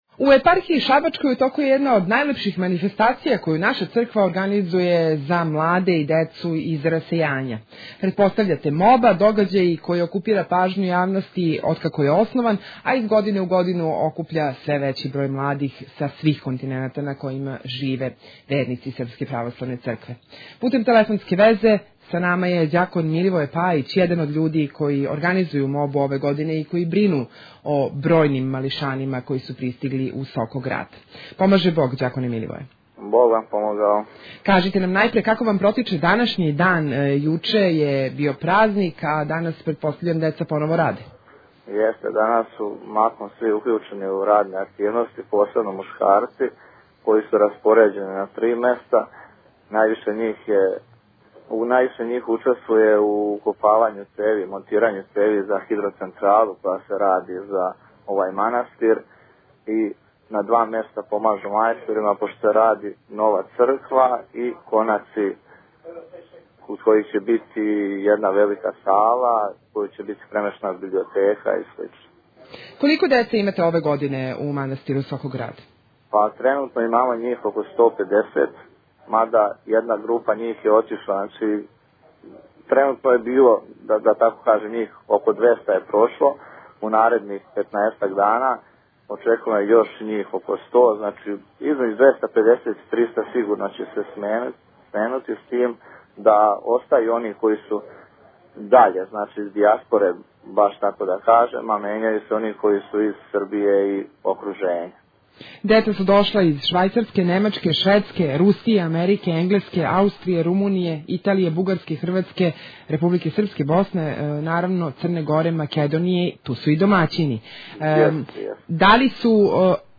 • Албум: razgovori